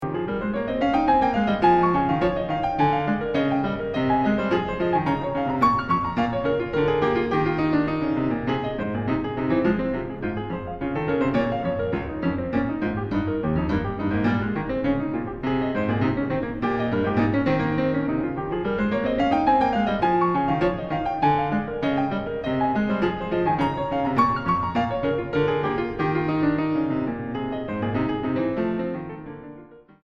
Tranquillo 1:07